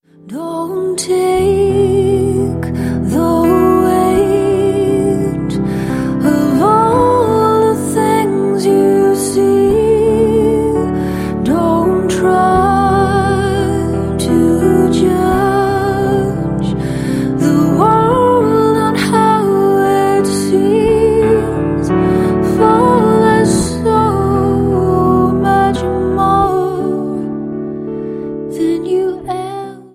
• Sachgebiet: Celtic